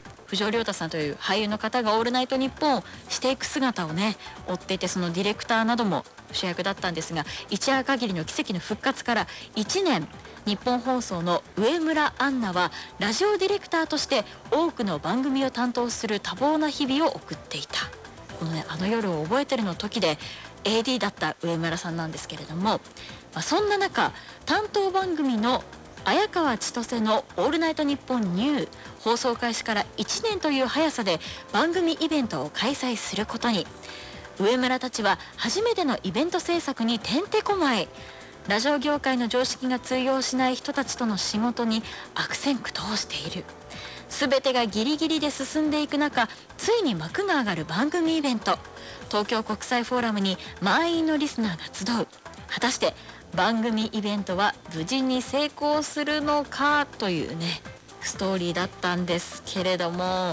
イヤホン端子に ＩＣ録音機を繋ぎ録音